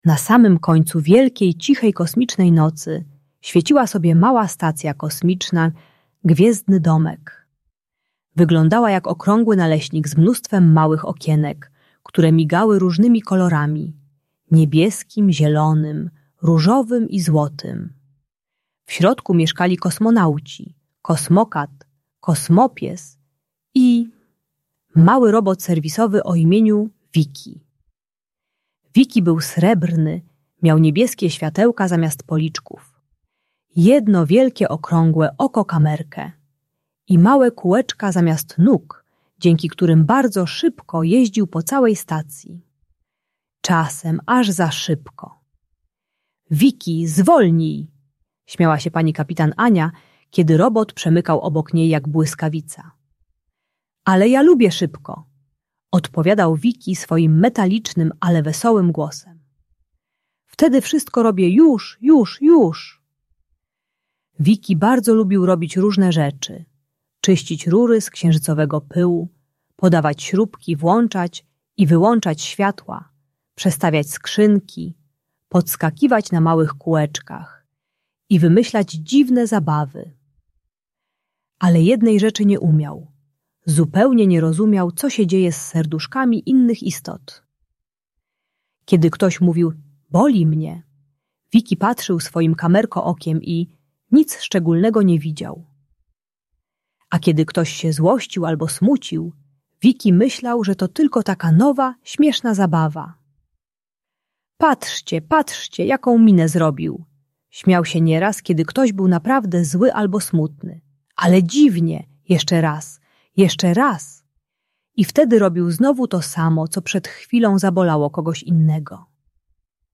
Audiobajka uczy techniki STOP-PATRZ-ZAPYTAJ, pomagając dziecku rozpoznawać, kiedy jego zachowanie rani innych.